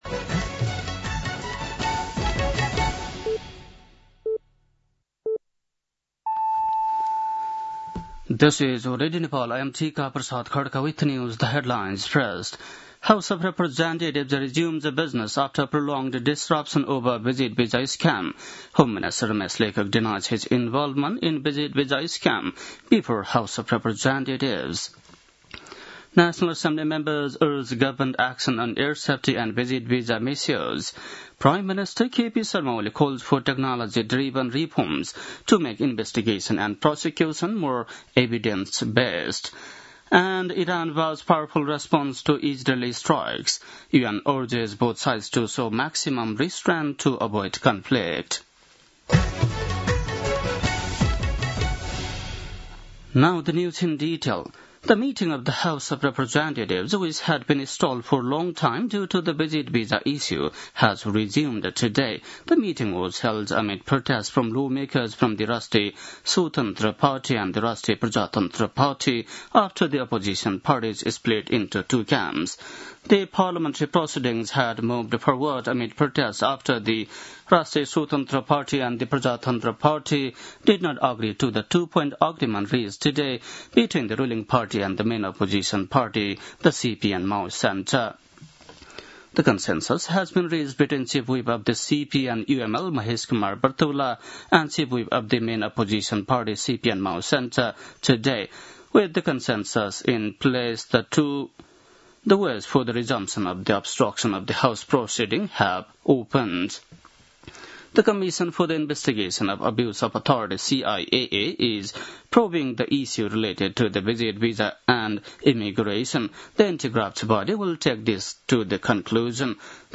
बेलुकी ८ बजेको अङ्ग्रेजी समाचार : ३० जेठ , २०८२